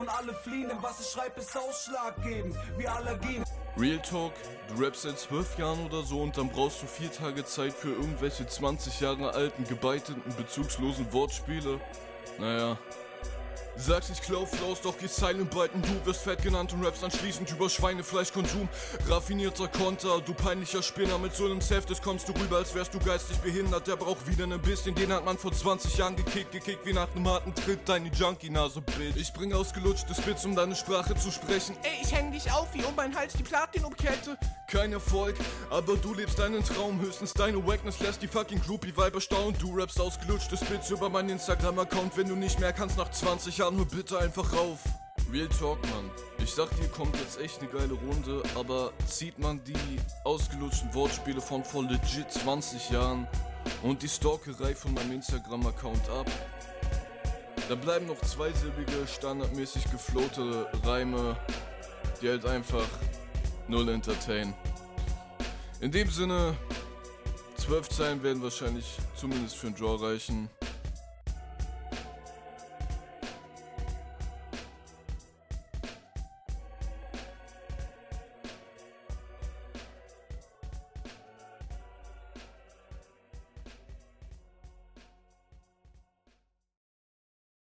Sound wie immer gut. Flow weiterhin auf einem soliden Level.